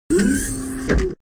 door open.wav